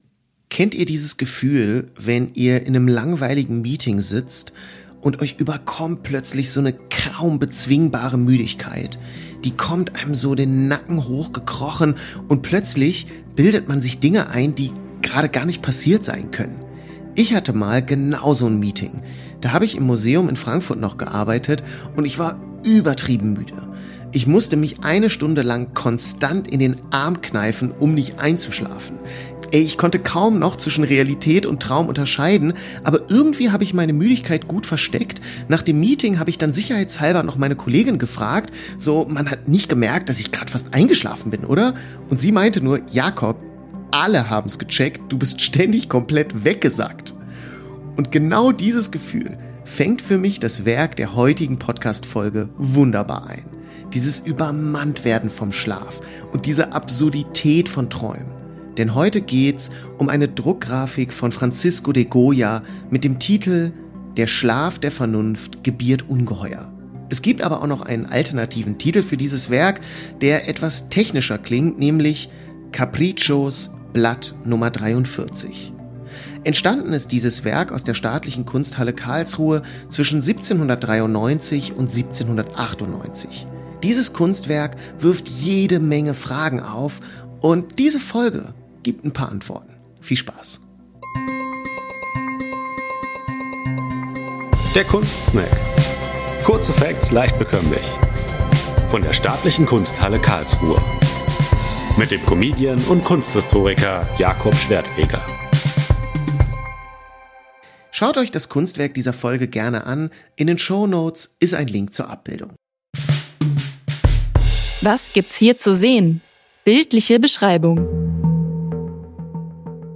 Sounddesign und Musik
Sprecher Intro und Outro
Sprecherin der Rubriken